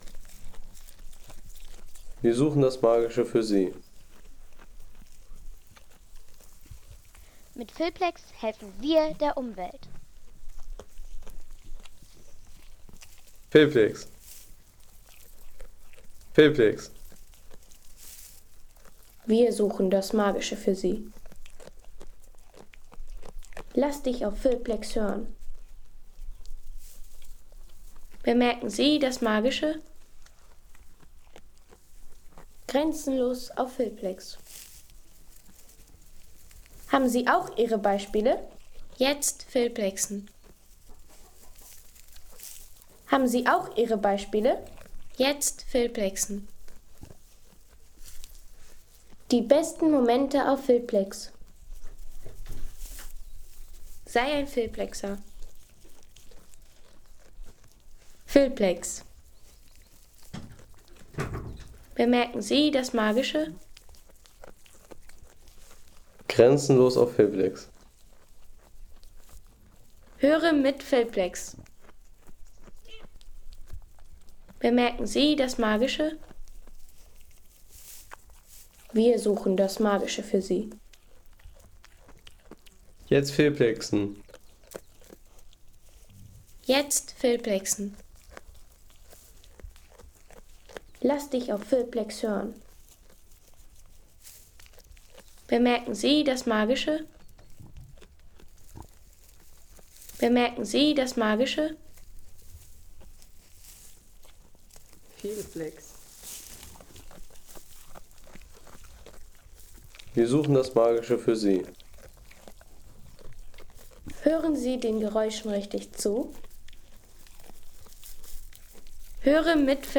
Schmatzen der Ziegen